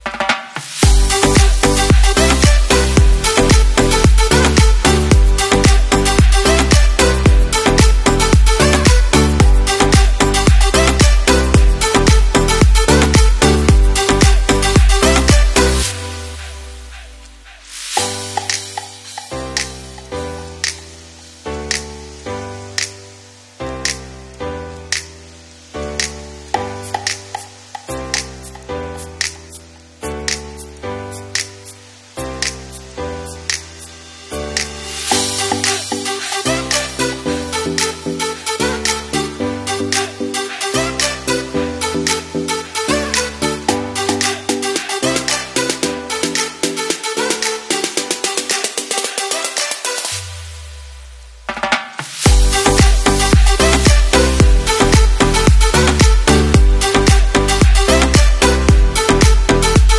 Opening Greek yogurt sounds🙏🏼 going sound effects free download